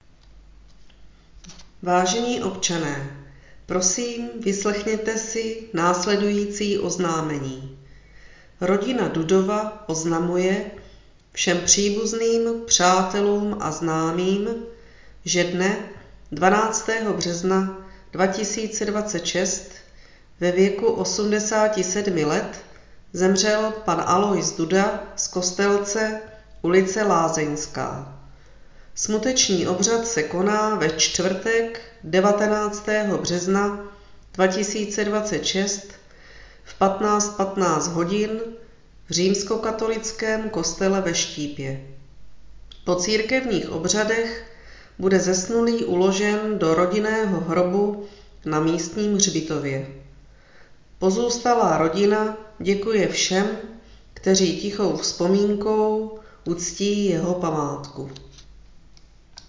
Hlášení místního rozhlasu